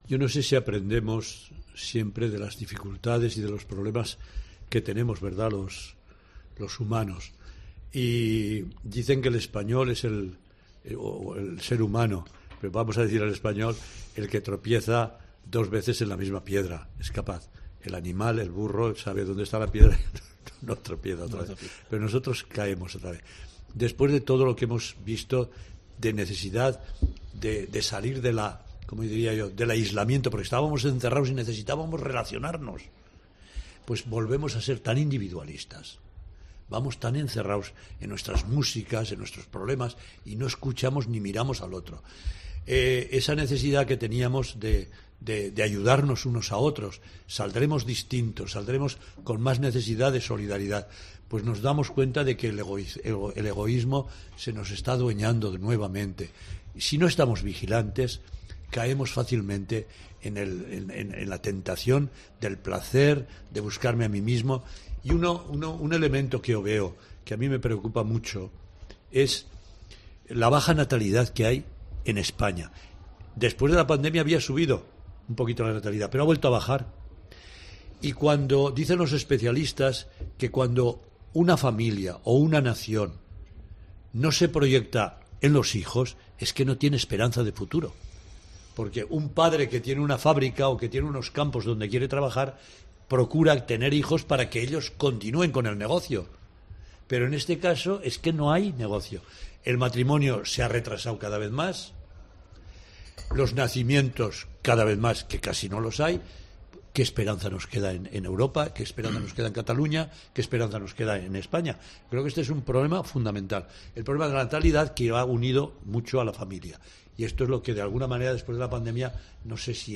Una inquietud que el cardenal Juan José Omella ha compartido en el programa 'Converses' de COPE Catalunya i Andorra.